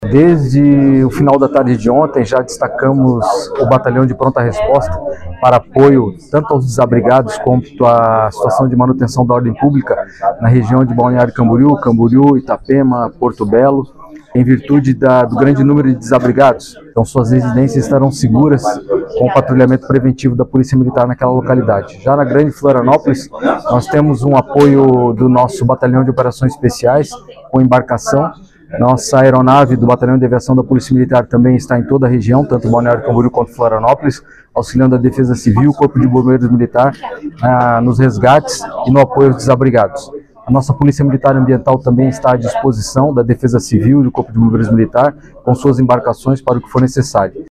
O subcomandante-geral da Polícia Militar de Santa Catarina, coronel Alessandro José Machado, destaca o trabalho da corporação, do patrulhamento preventivo, o auxílio à Defesa Civil e o serviço da Polícia Ambiental:
SECOM-Sonora-subcomandante-geral-da-Policia-Militar-de-Santa-Catarina.mp3